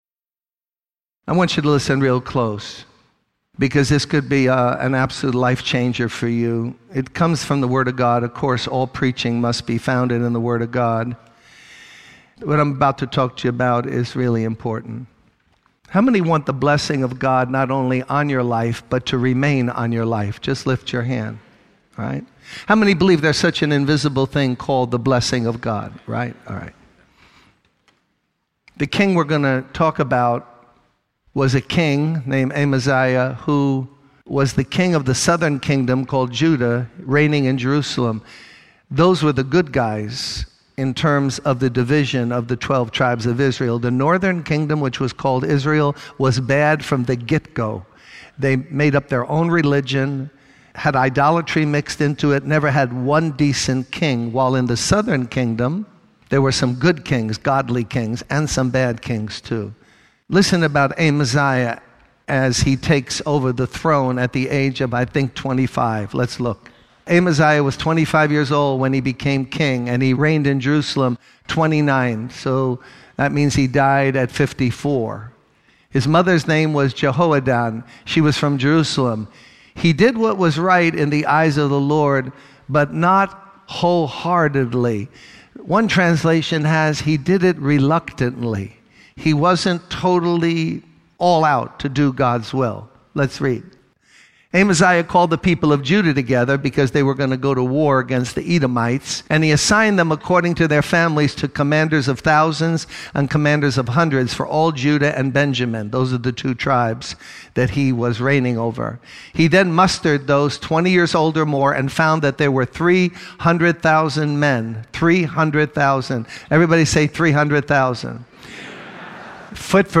In this sermon, the preacher emphasizes the importance of choosing our alliances wisely. He uses the story of a king who hired mercenaries to go into battle, but a brave prophet warned him that God was not with them. The preacher warns against forming alliances with godless individuals, even if they may be charismatic or family members.